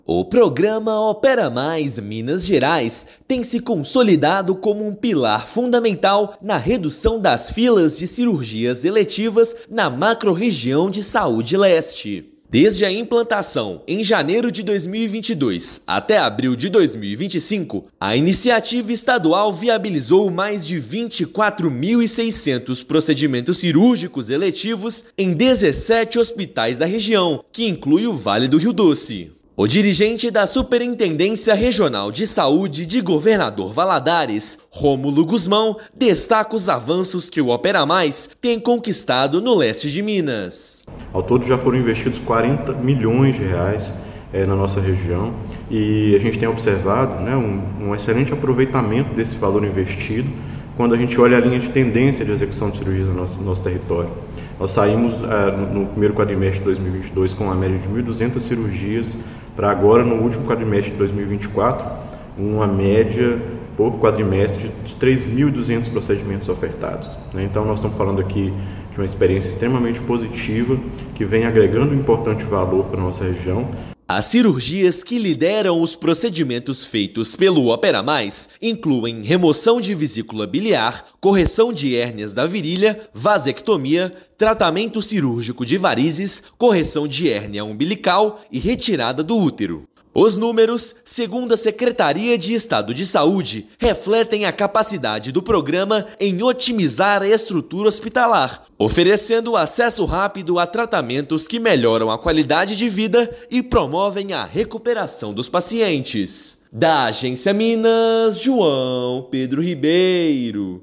Investimentos estaduais possibilitaram a realização de quase 25 mil cirurgias eletivas na região. Ouça matéria de rádio.